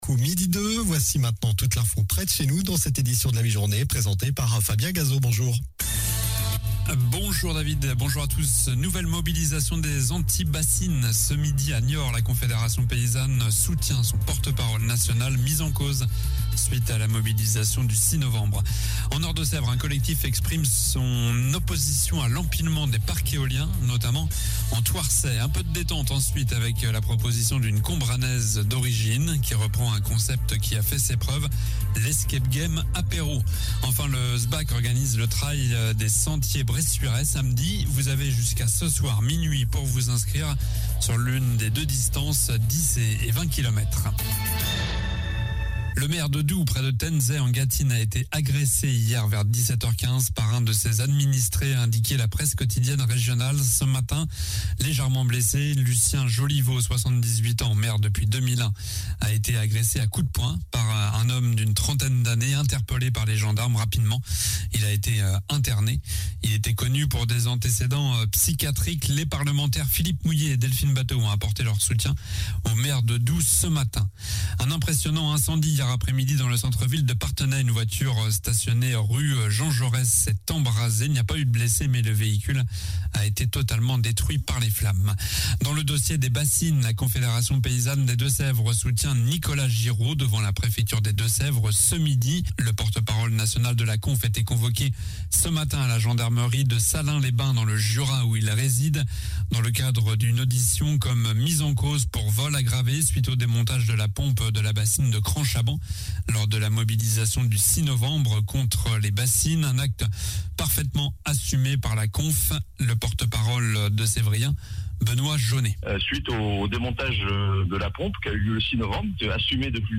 Journal du jeudi 17 février (midi)